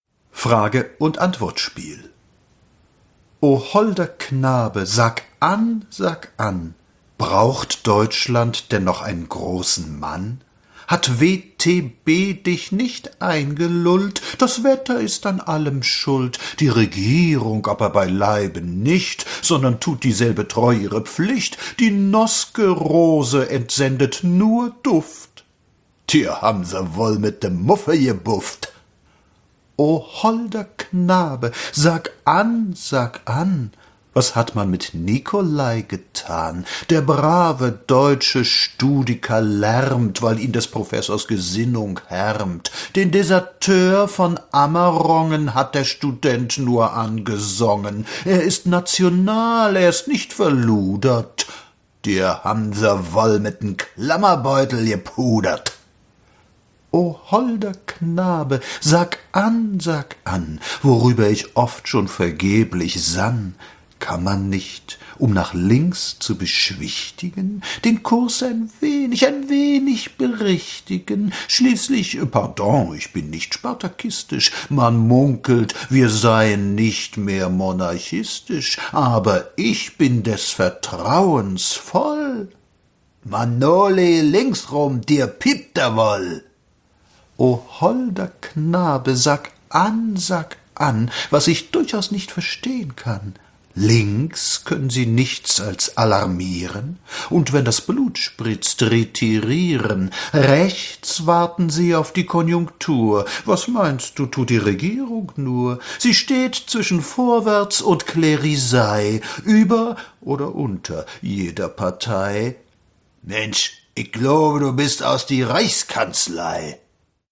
Der Sprach- und Sprechkünstler